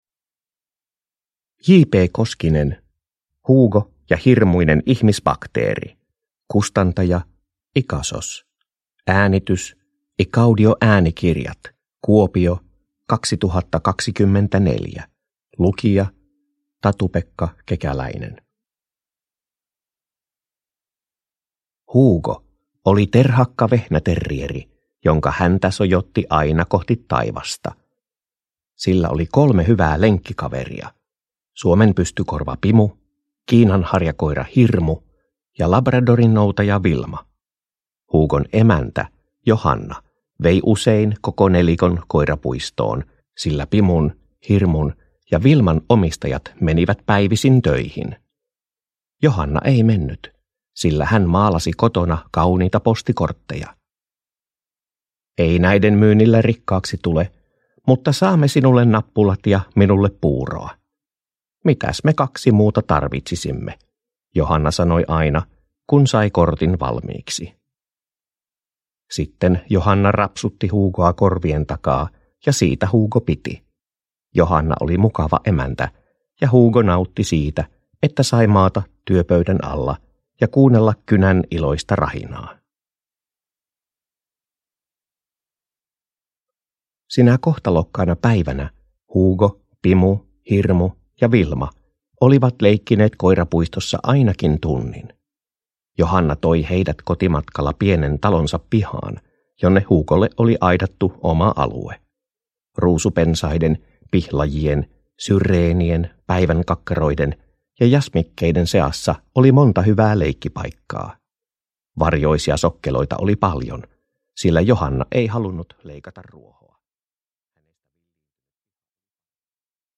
Hugo ja hirmuinen ihmisbakteeri – Ljudbok